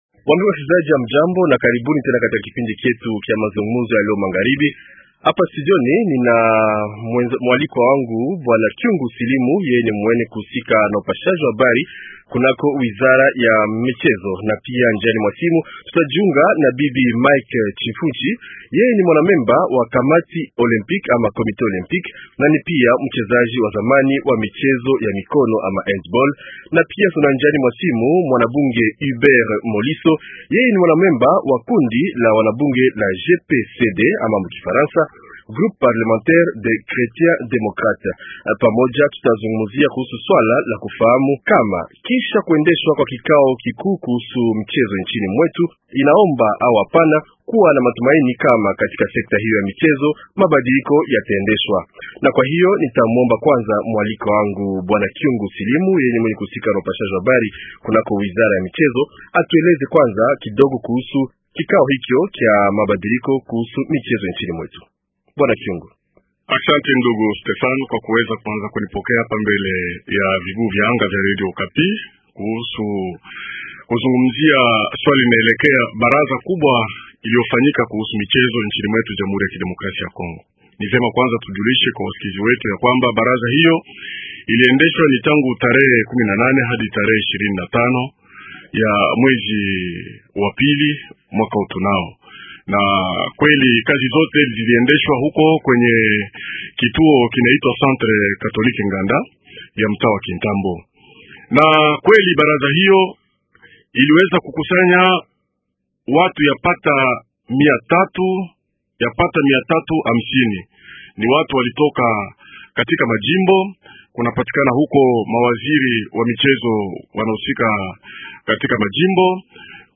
Hubert MOLISO : Mwanabunge wa taifa